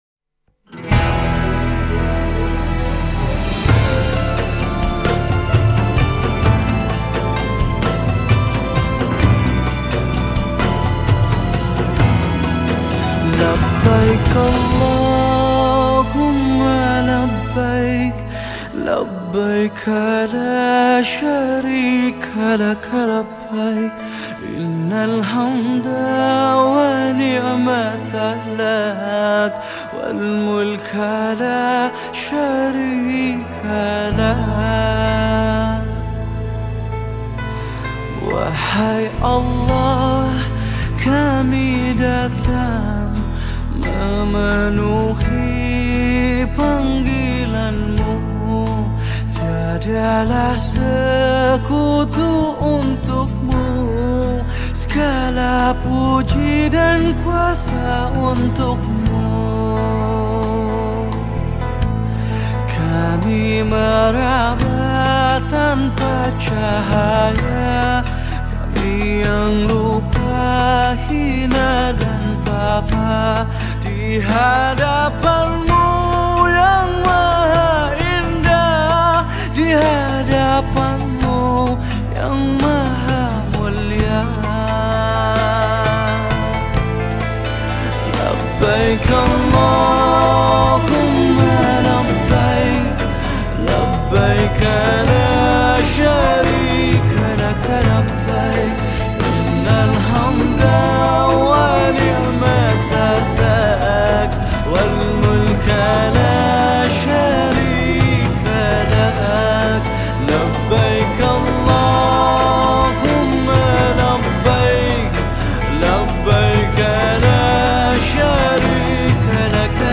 Arabic Songs , Nasyid Songs